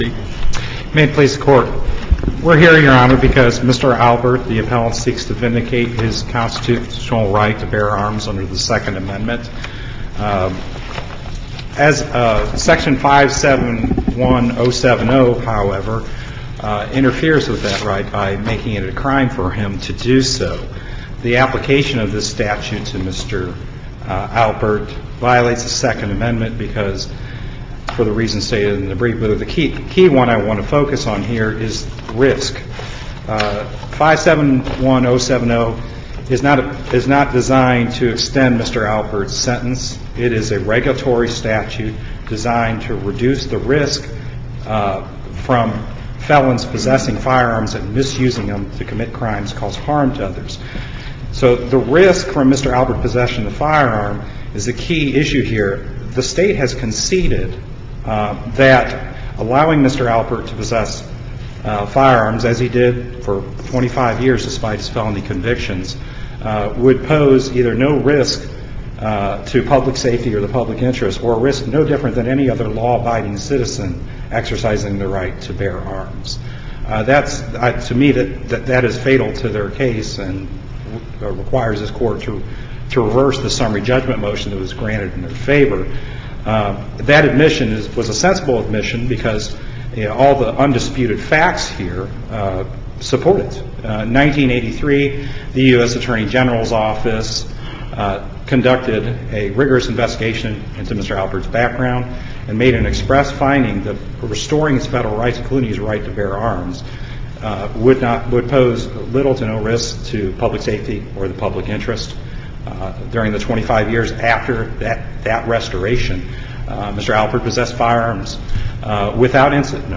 MP3 audio file of arguments in SC96032